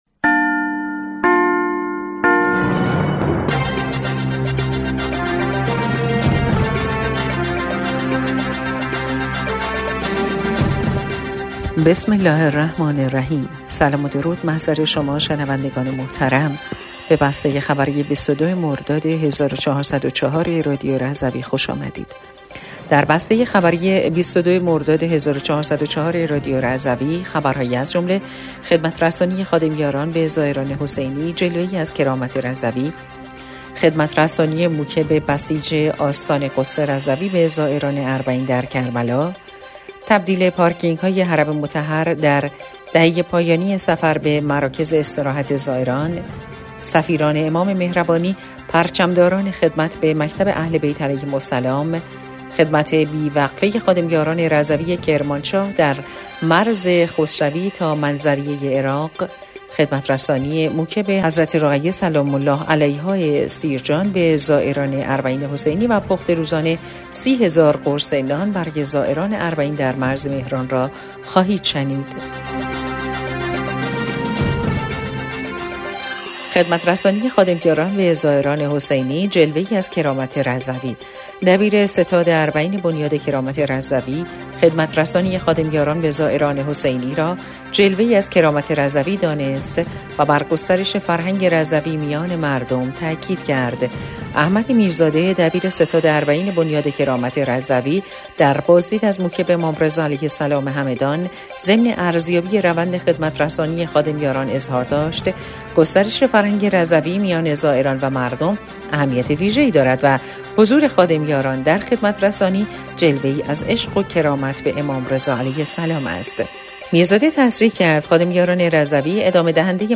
بسته خبری ۲۲ مردادماه ۱۴۰۴ رادیو رضوی/